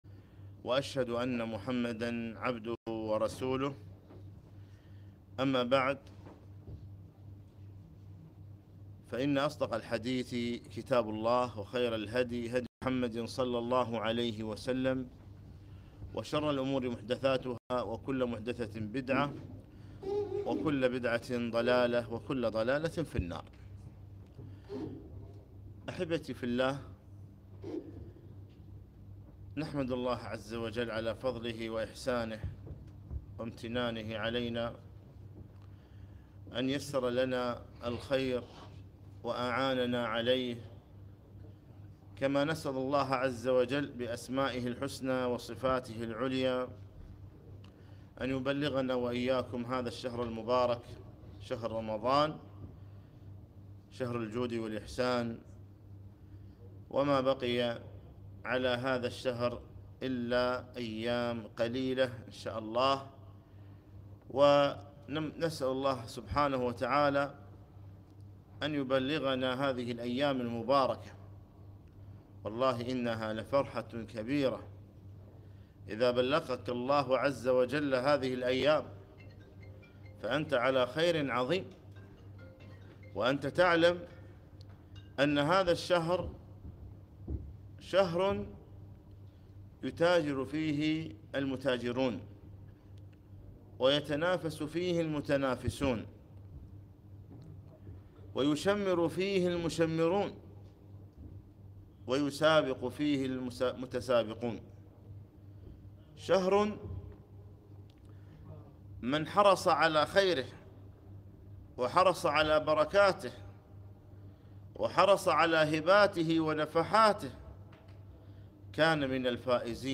محاضرة - أقبل لتكن من المقبولين